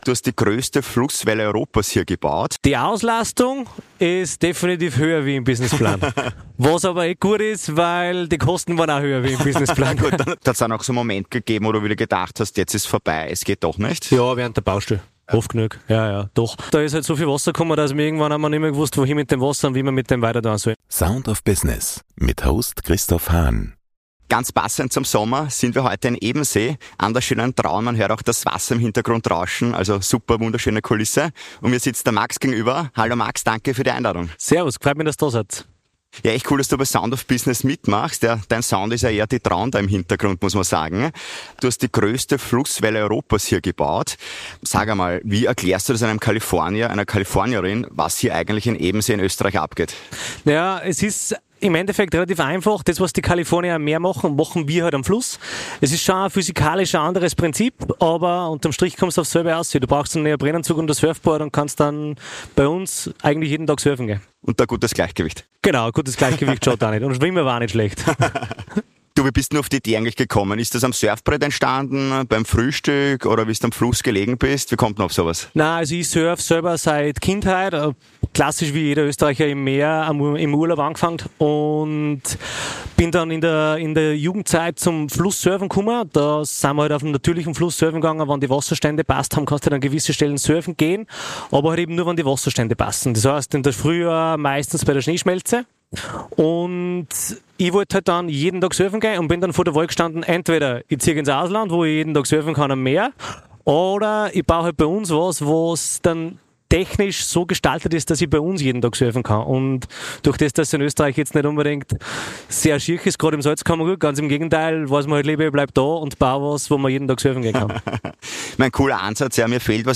Heute im Gespräch: